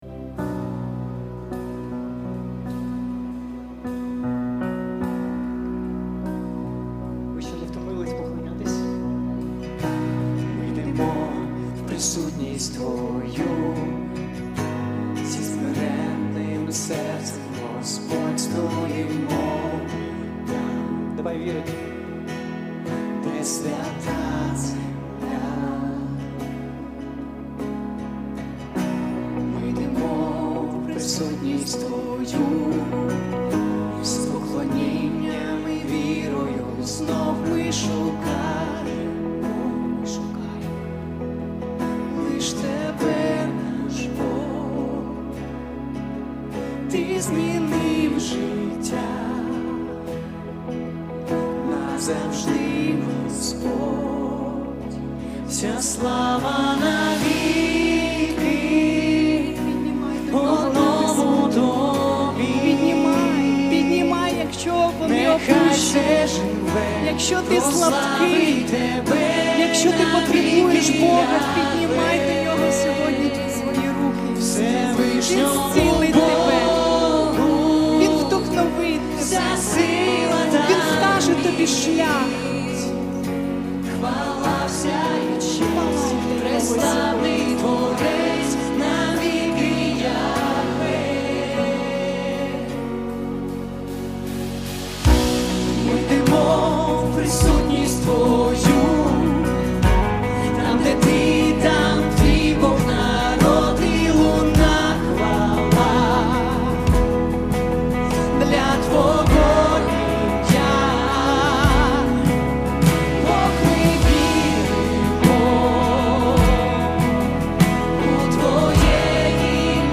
166 просмотров 160 прослушиваний 1 скачиваний BPM: 152